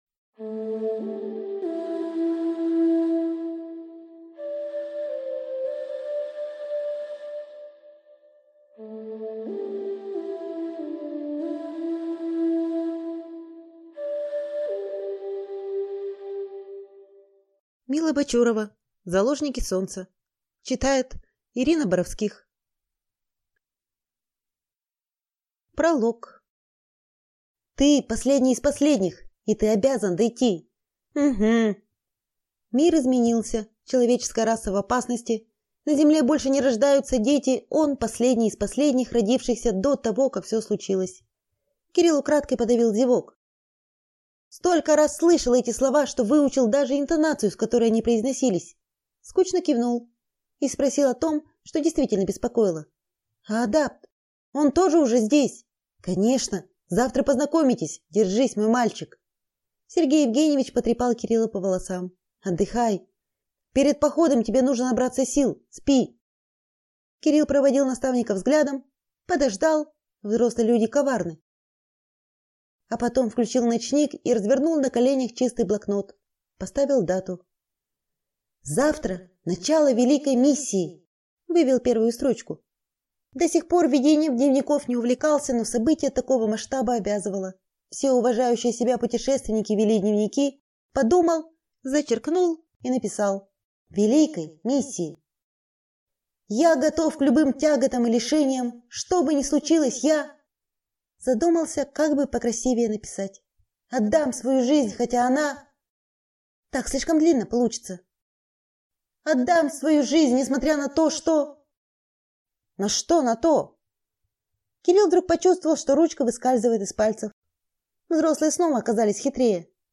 Аудиокнига Заложники солнца | Библиотека аудиокниг